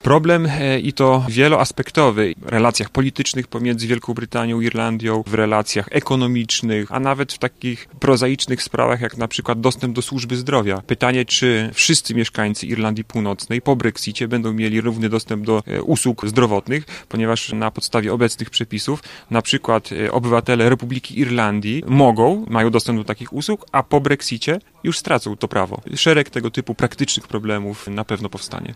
Irlandia po brexicie – dyskusja podczas Dni Świętego Patryka
W Centrum Brytyjskim UMCS w Lublinie przy ul. Zuchów trwa panel poświęcony społeczeństwu i polityce współczesnej Irlandii.